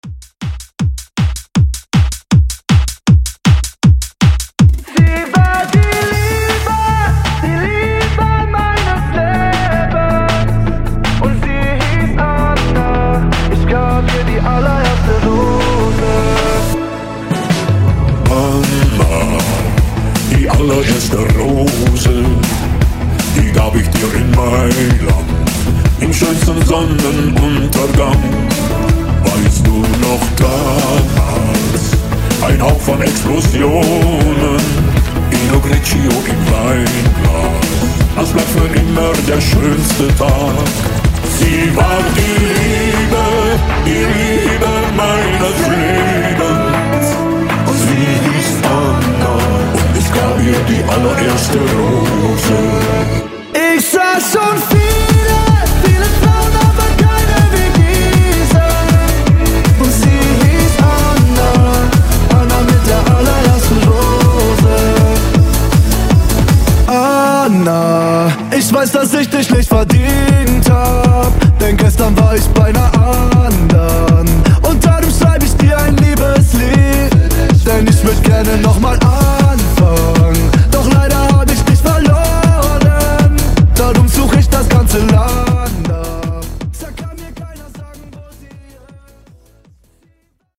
Genres: DANCE , EDM , GERMAN MUSIC
Clean BPM: 158 Time